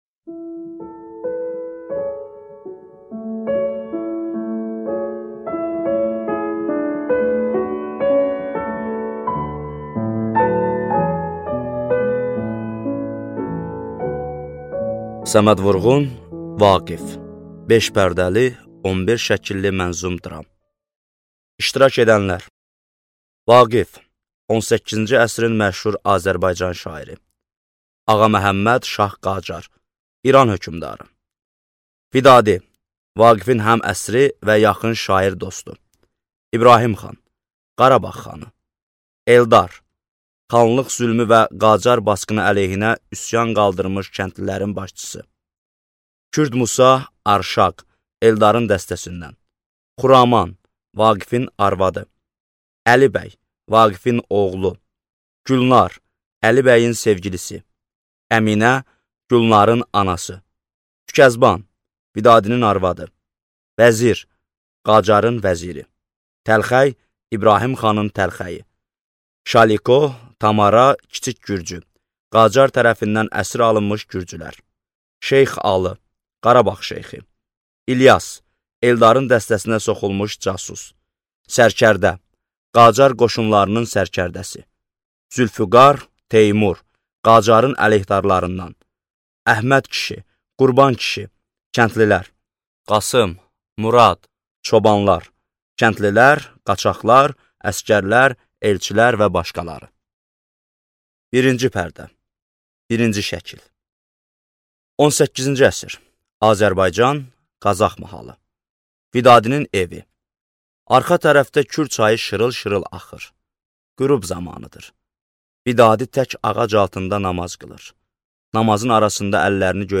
Аудиокнига Vaqif | Библиотека аудиокниг